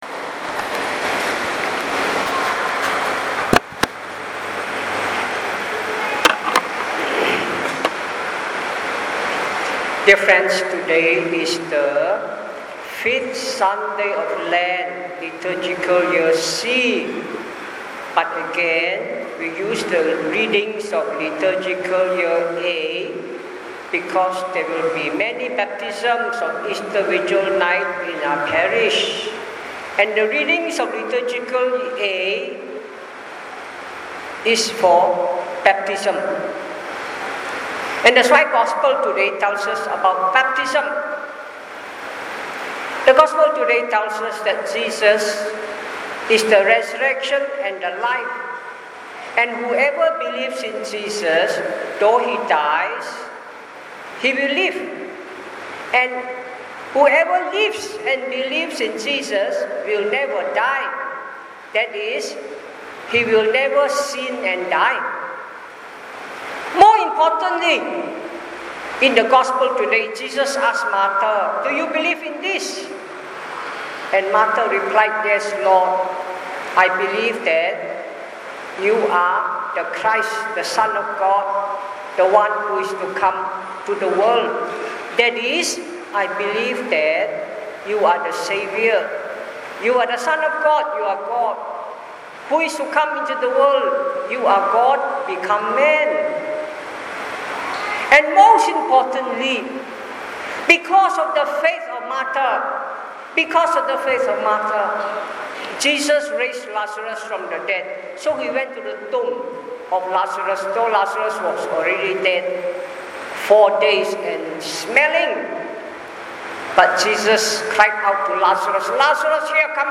5th Sunday of Lent (Year C) – 07th April 2019 – English Audio Homily